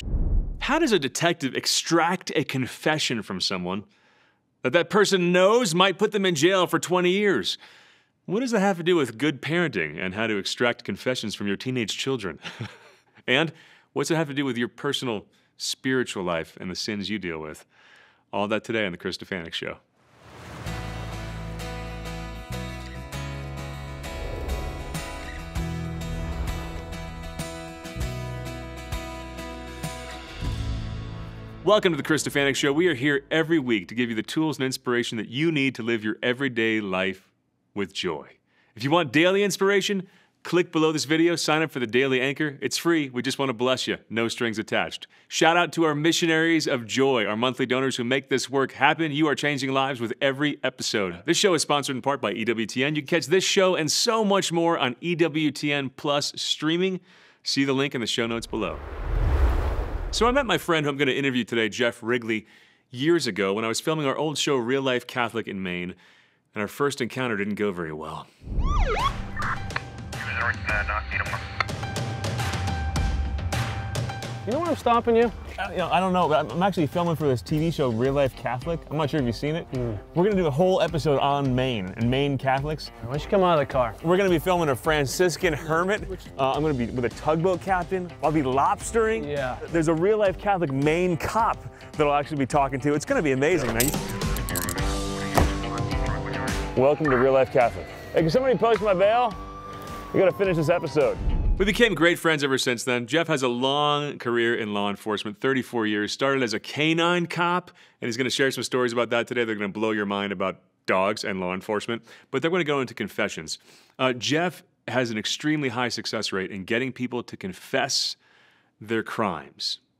In this eye-opening conversation